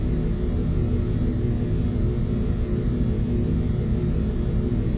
multiple_motors_3_larger.wav